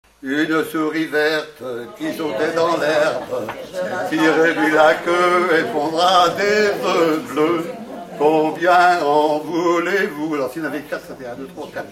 Chavagnes-en-Paillers
enfantine : pour autres jeux
Pièce musicale inédite